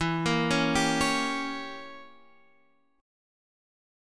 Edim7 chord